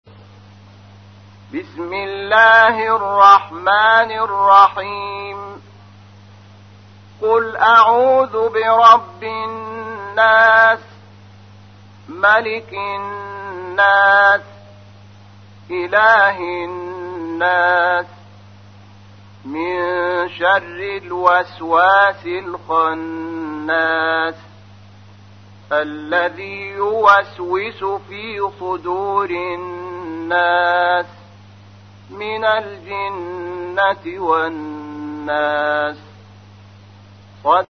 تحميل : 114. سورة الناس / القارئ شحات محمد انور / القرآن الكريم / موقع يا حسين